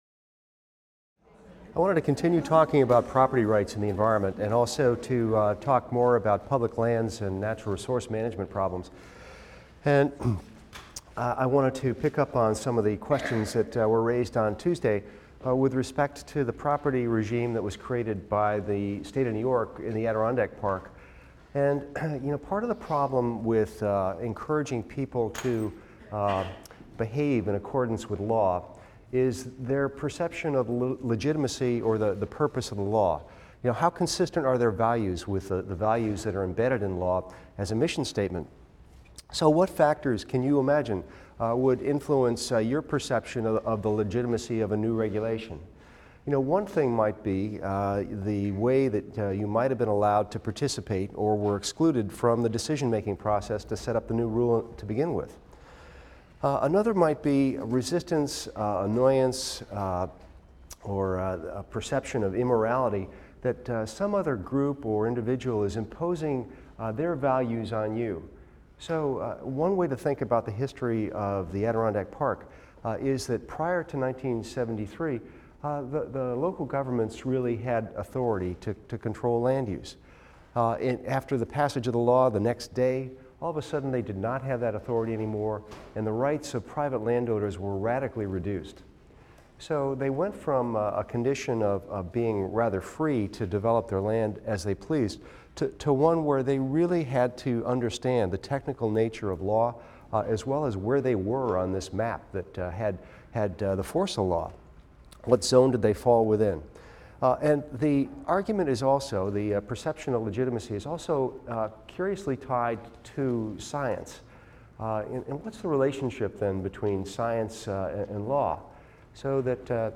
EVST 255 - Lecture 18 - Property Rights and Public Lands Management | Open Yale Courses